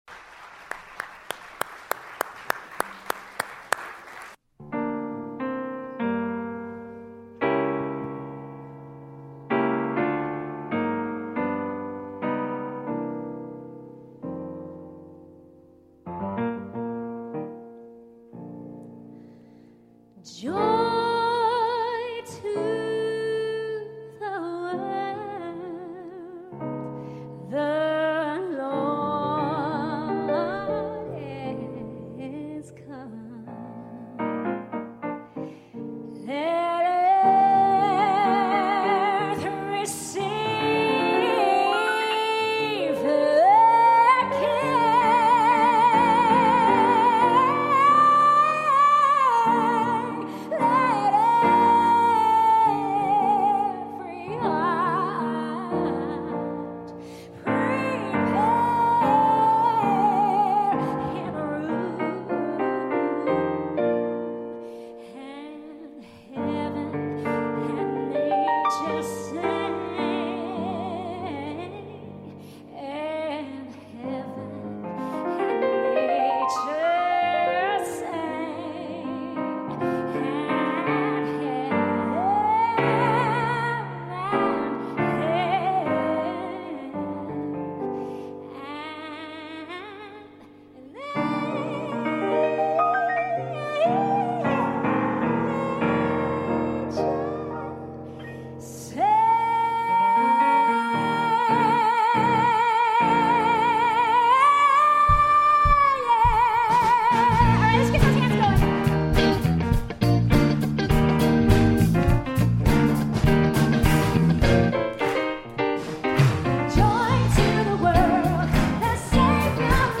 South Coast Christian Assembly Christmas Concert
It’s a rough and raw clip, but it’ll give you an idea of how much fun we had.